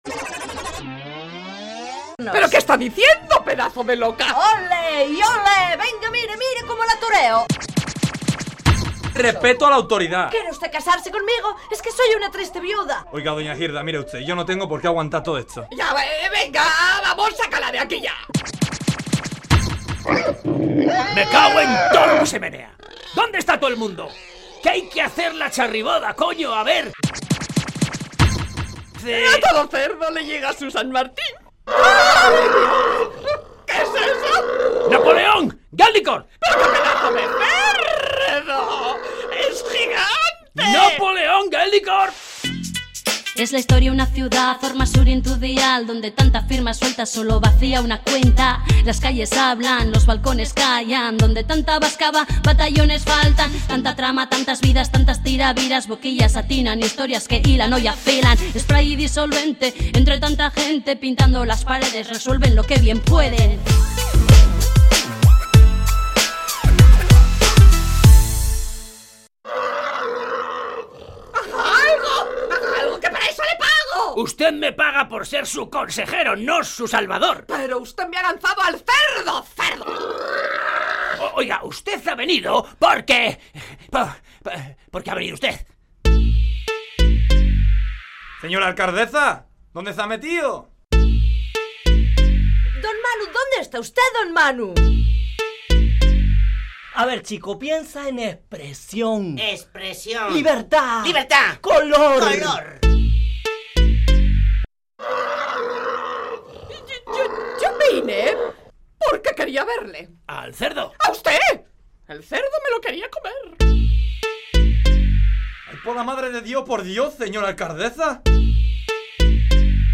Audio: Entrega número 26 en el Graffiti de Radio Euskadi de la radio-ficción "Spray y Disolvente", escrita y dirigida por Galder Pérez